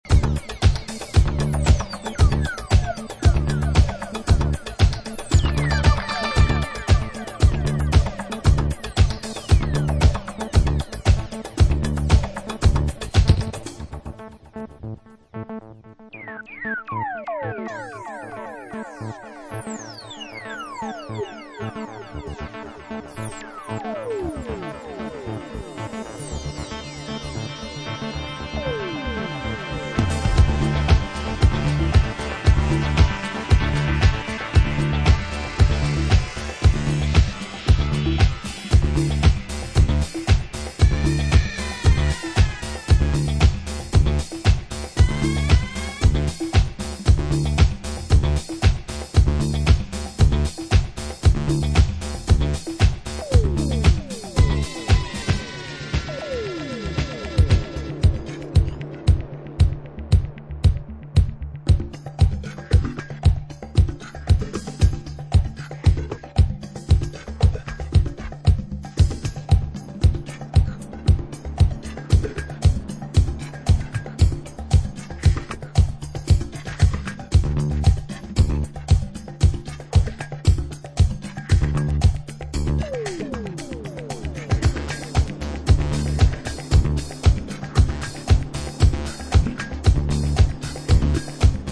disco-dub